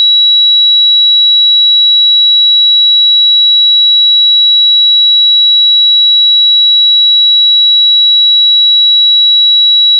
A suitable 3900 Hz sine wave can be downloaded
sine-wave-3900-hz.wav